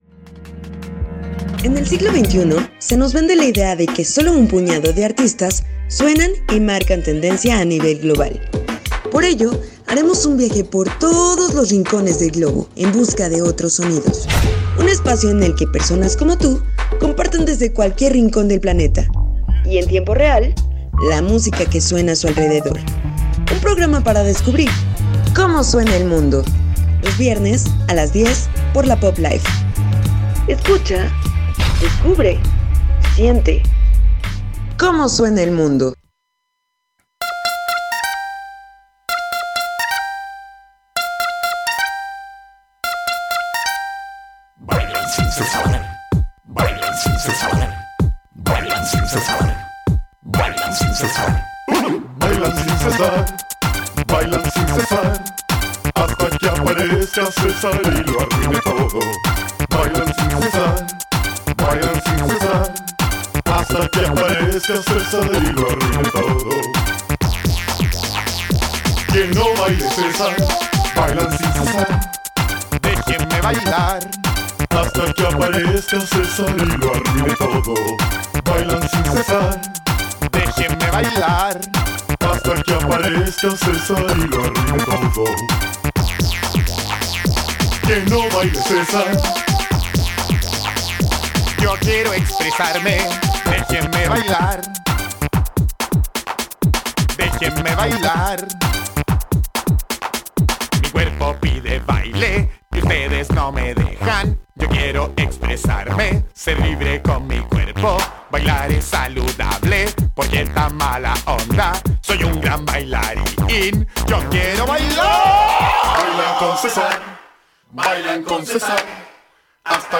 Un viaje por todos los rincones del globo, en busca de otros sonidos que nos comparten en directo melómanos y periodistas, desde su propia ciudad.